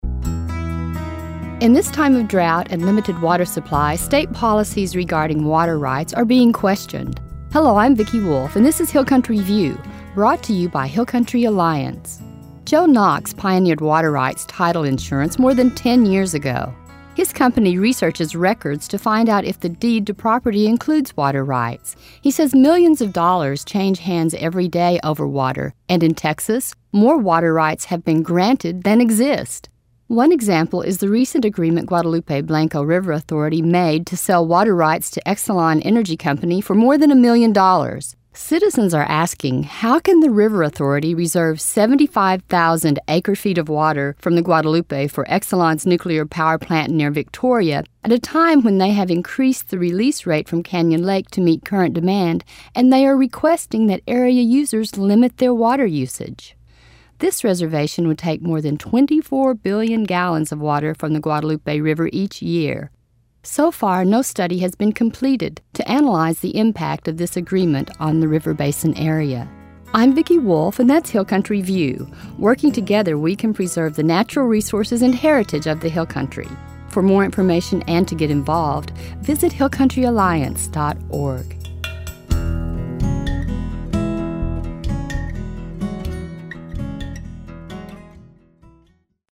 90 Second Radio Spots